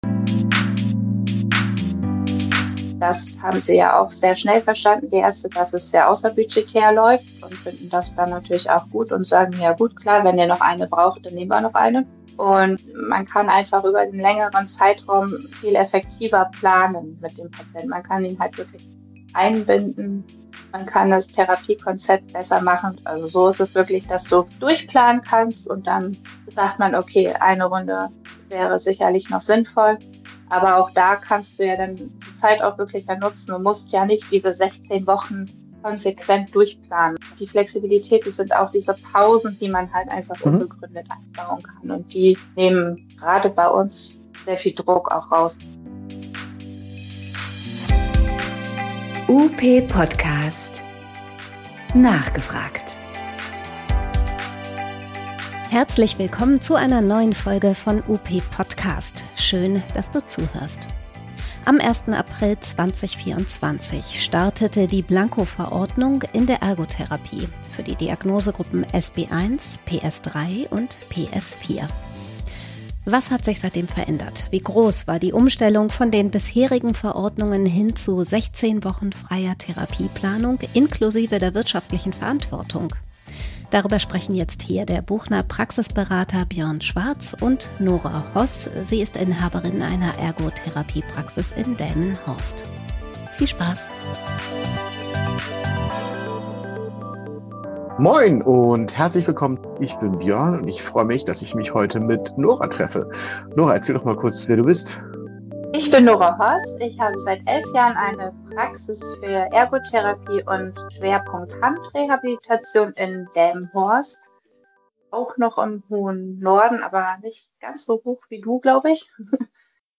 Wir sprechen im Podcast mit einer Praxisinhaberin.